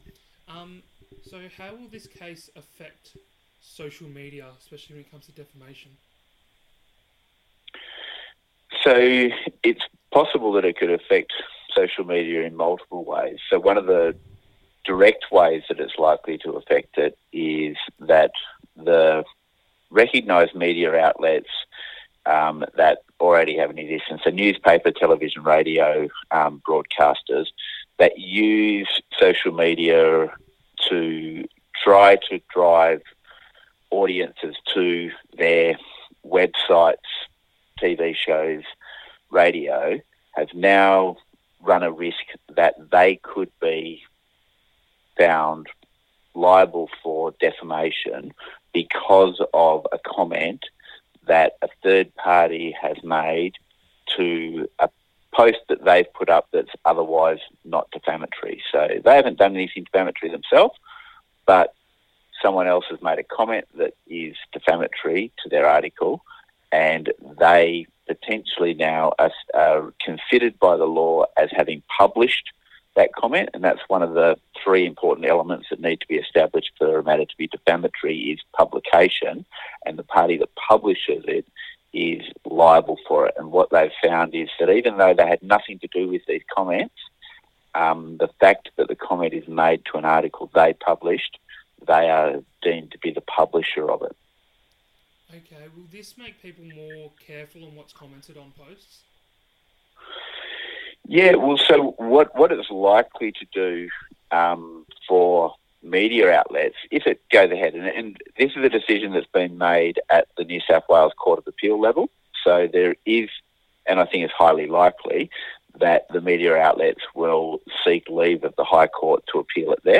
Defamation Interview on ECU Radio